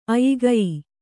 ♪ ayigayi